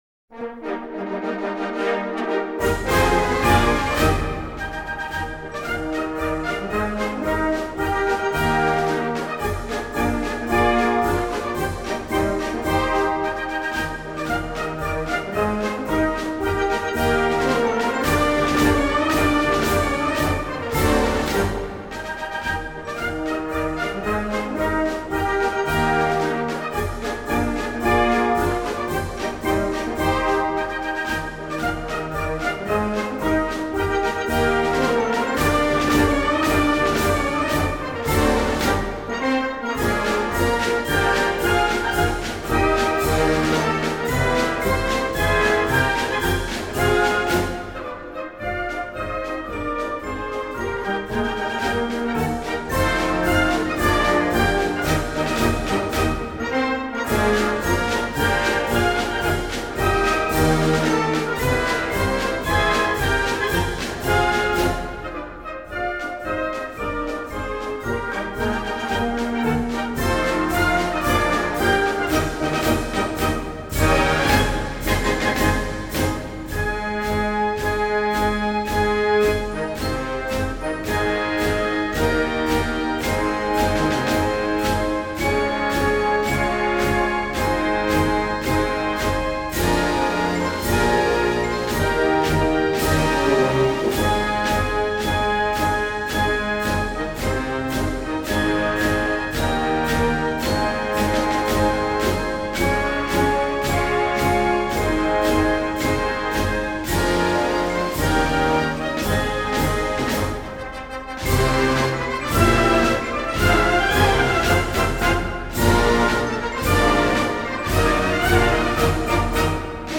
Heeresmusikkorps Koblenz 2015
audio-2010-musikstueck-hmk-koblenz-data.mp3